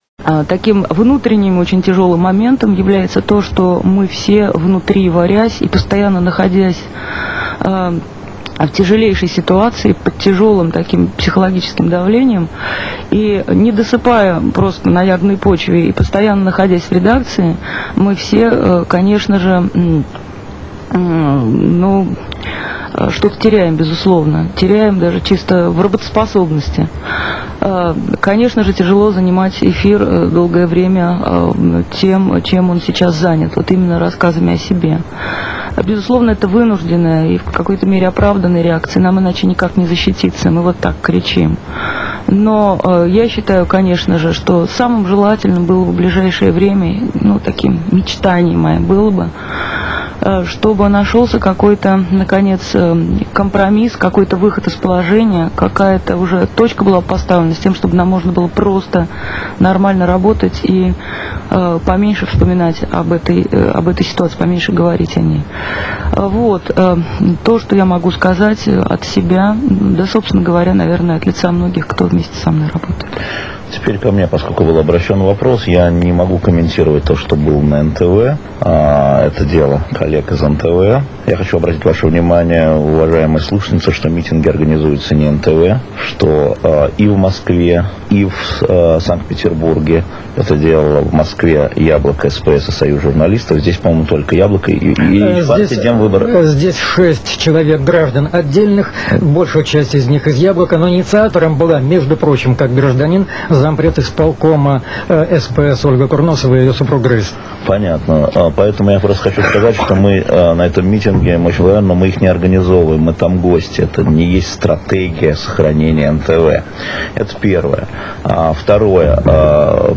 Интервью Светланы Сорокиной и Алексея Венедиктова на радио "Эхо Петербурга"